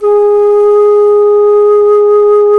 Index of /90_sSampleCDs/Roland LCDP04 Orchestral Winds/FLT_Alto Flute/FLT_A.Flt vib 1